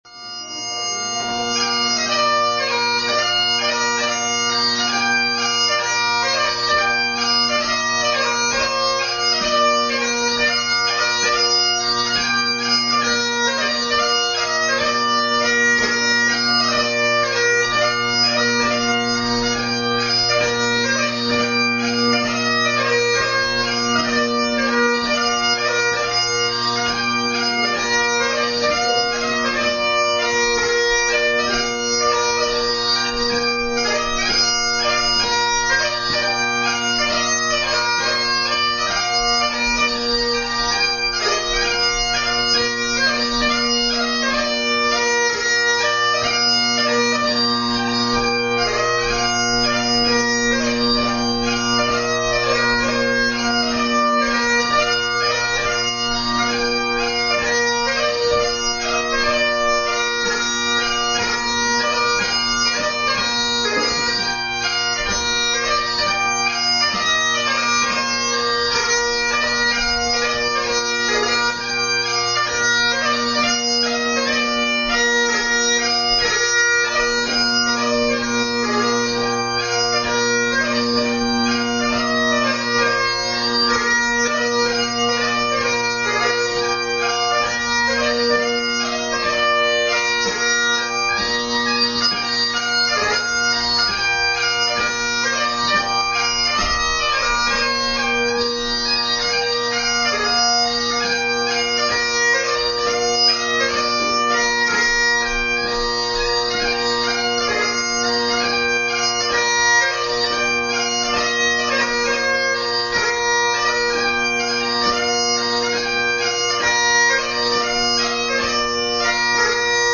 Extracts from 2005 competition
Juvenile March